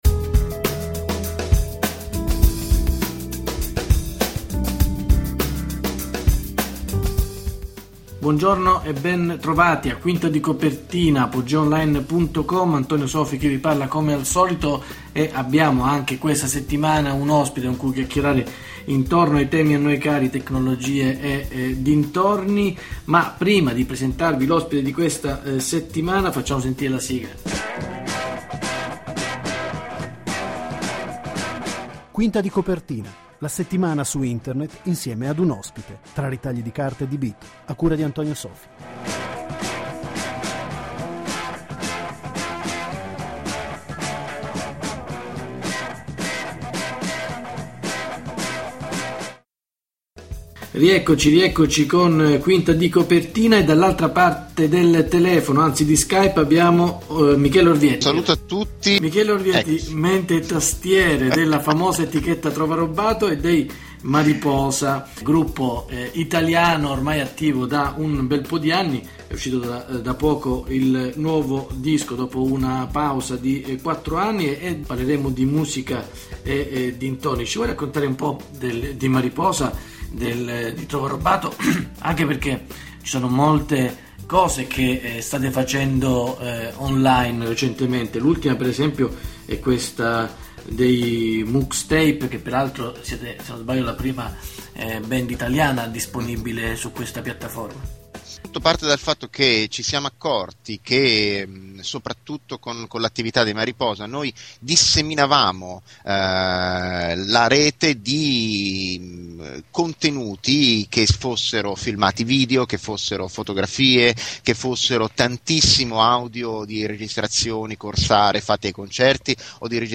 Venti minuti in podcast tra ritagli di carta e bit, in compagnia di un ospite.